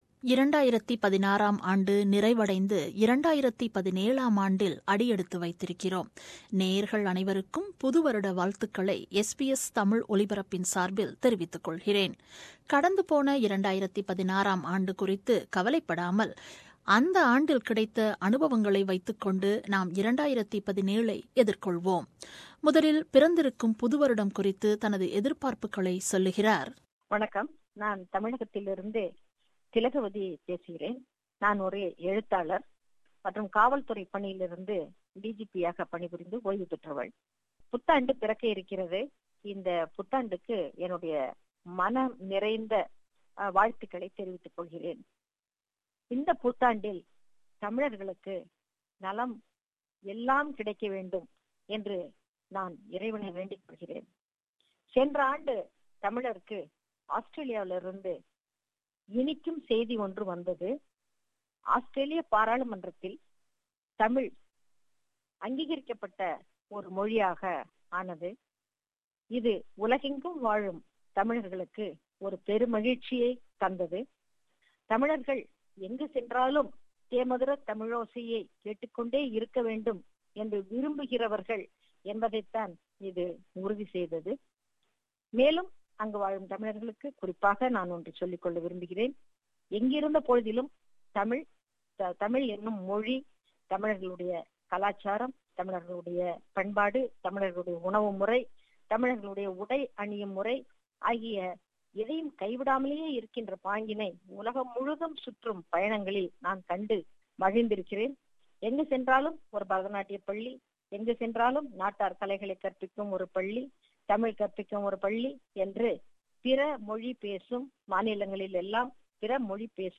2017 - New Year Speech